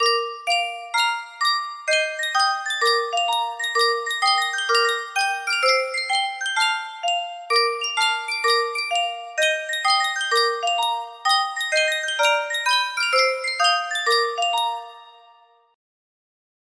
Yunsheng Music Box - Sidewalks of New York 6488 music box melody
Full range 60